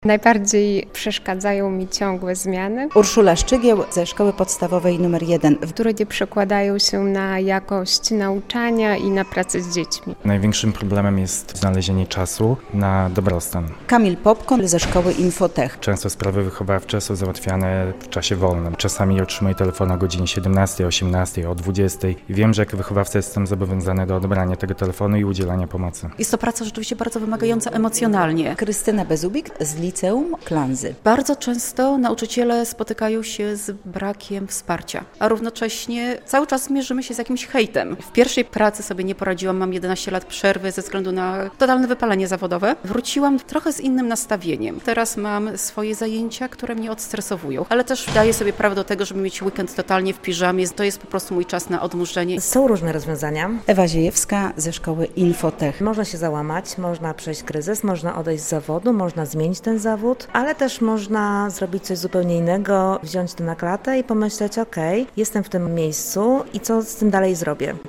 Jej uczestnicy mówili, jak sobie z tym radzą i z jakimi problemami borykają się w codziennej pracy.